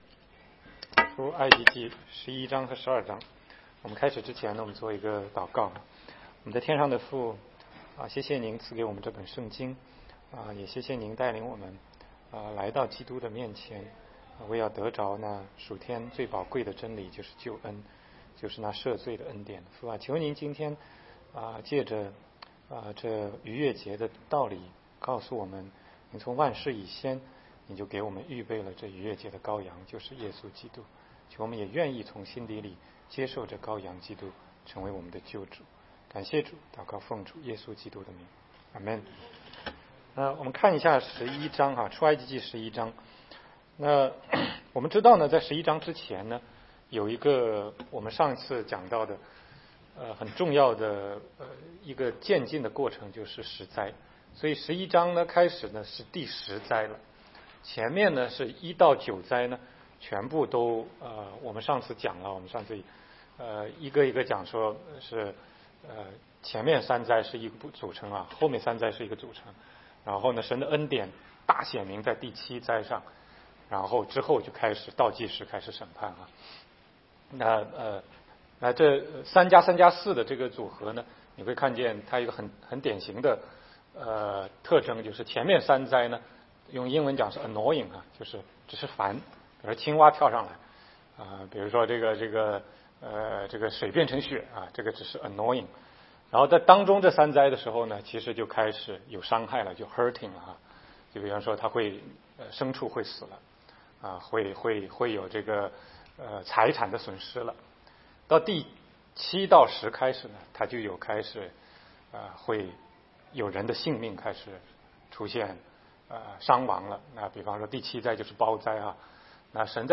16街讲道录音 - 逾越节的羔羊